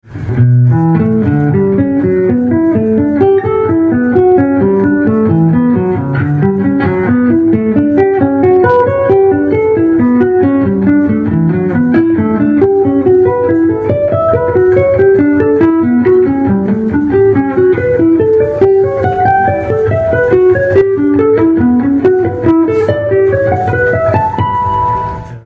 Kurt Rosenwinkel Diatonic 4ths Exercise This is an excerpt taken from a Masterclass given by Kurt Rosenwinkel. He talks about how he makes up exercises for himself. He demonstrates this study which he describes as diatonic 4ths going up in diatonic thirds, then up a step, and returning down again in diatonic thirds.
Kurt-Rosenwinkel-Diatonic-4ths-Exercise.mp3